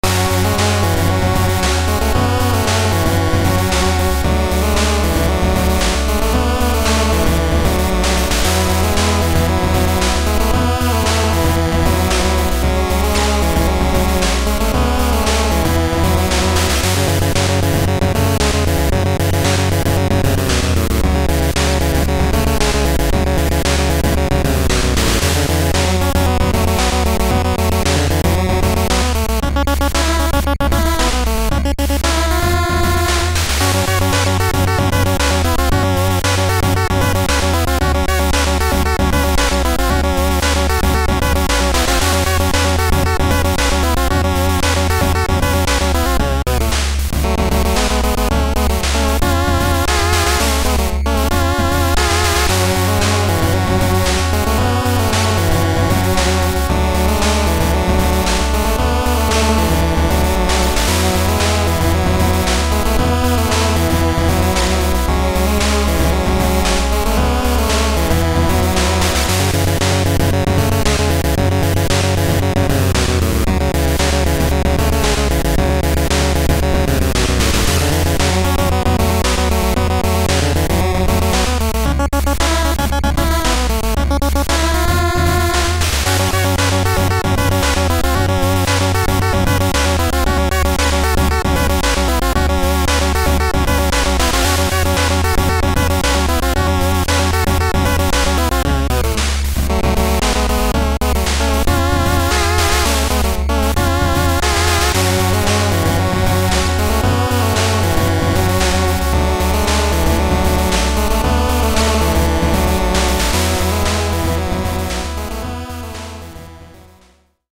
I aimed for a NES (no limitations) style given what I’ve shown the game would look like.
So far, I feel the music does give an adventure-retro vibe.
i think it could go without the repeat around 0:45 and 1:38 (i see what its going for but it doesn’t really work in my opinion)